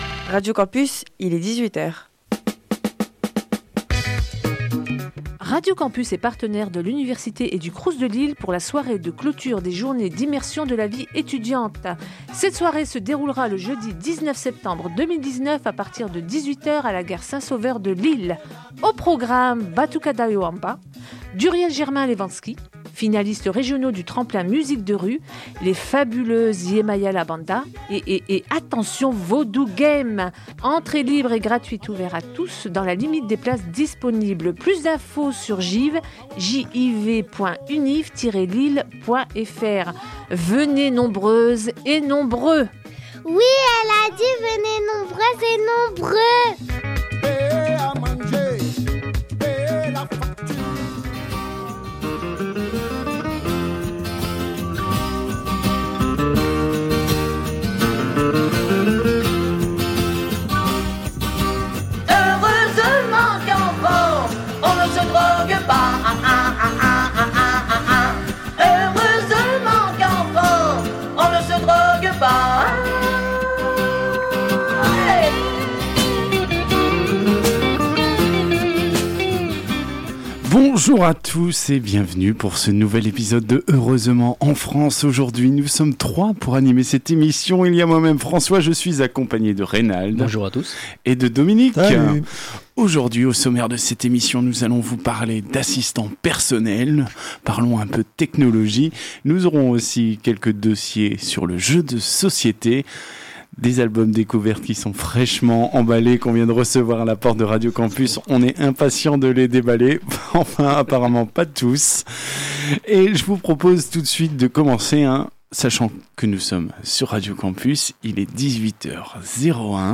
Au sommaire de cet épisode diffusé le 15 septembre 2019 sur Radio Campus 106,6 :